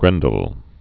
(grĕndl)